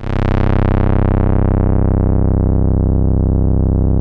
MG TAURUS 1.wav